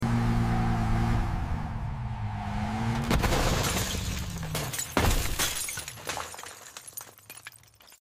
SEAT Crash ASMR | PT3 sound effects free download